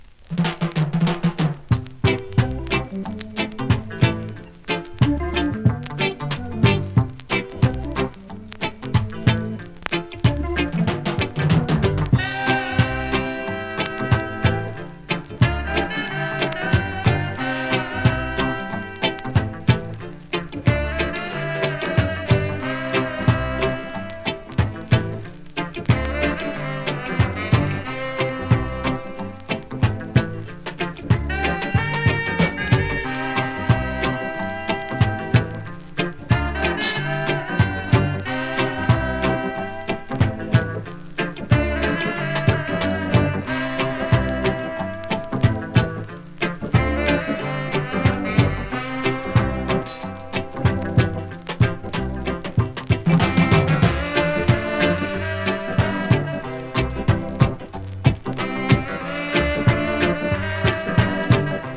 2026!! NEW IN!SKA〜REGGAE
スリキズ、ノイズ比較的少なめで